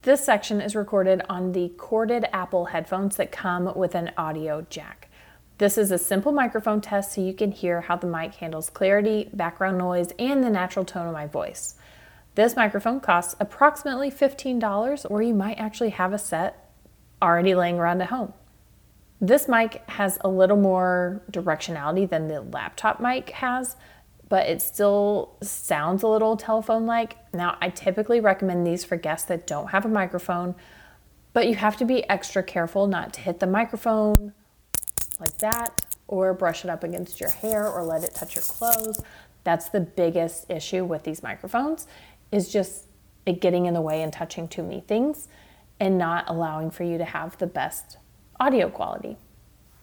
Apple-Corded-Headphones.mp3